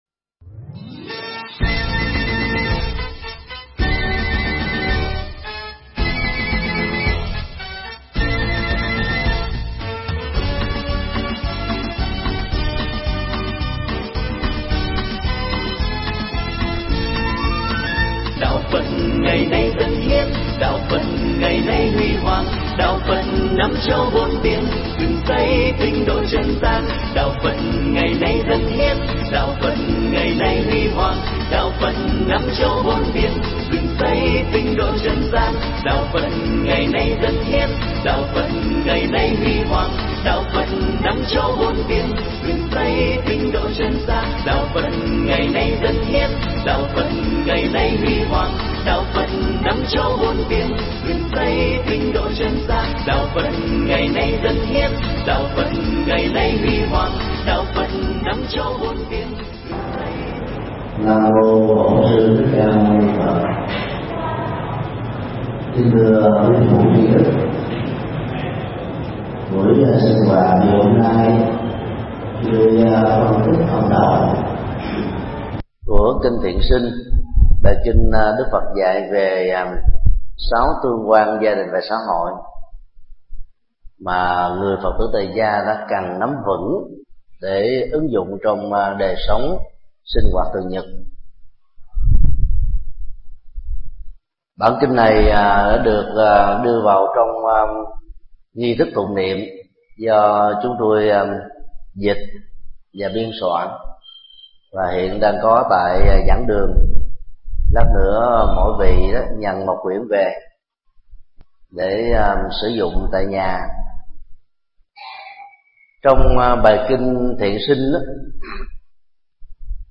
Tải mp3 Thuyết Pháp Kinh Thiện Sanh 01: Đạo vợ chồng – Thầy Thích Nhật Từ giảng tại chùa Ấn Quang, ngày 21 thán 8 năm 2011